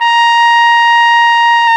Index of /90_sSampleCDs/Roland L-CDX-03 Disk 2/BRS_Trumpet 1-4/BRS_Tp 1 Class